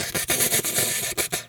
snake_2_hiss_04.wav